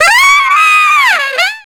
Index of /90_sSampleCDs/Zero-G - Total Drum Bass/Instruments - 3/track67 (Riffs Licks)